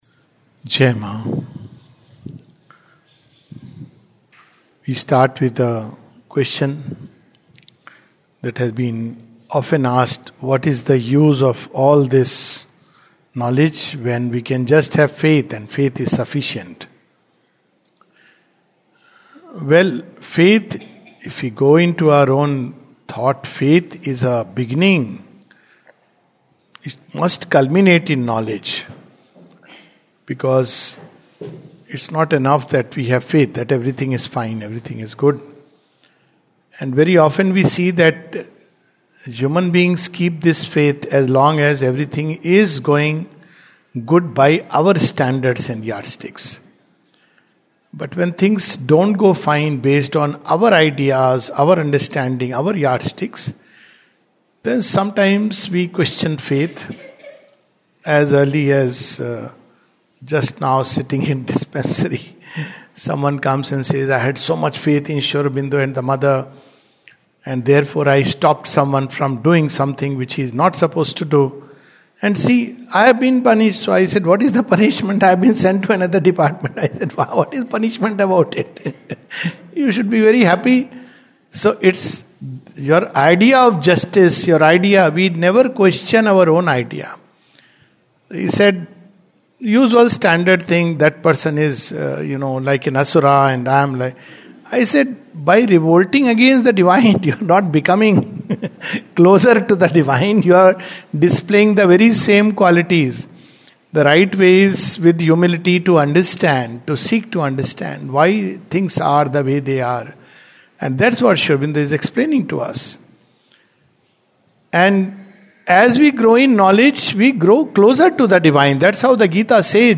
The Life Divine Study Camp, 25th February 2026, Session # 06-07 at Sri Aurobindo Society, Pondicherry - 605002, India. The Divine and the Undivine (Part 3).